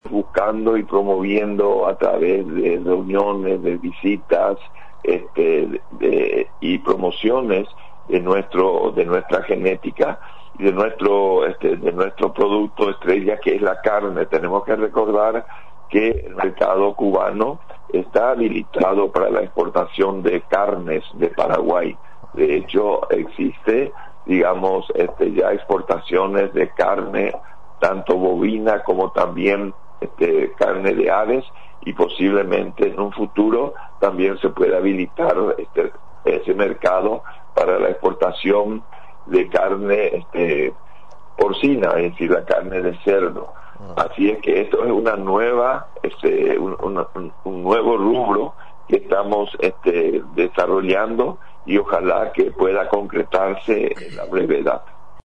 En conversación con Radio Nacional del Paragua, explicó que la comitiva del país caribeño, mantuvo una reunión con las autoridades del Senacsa.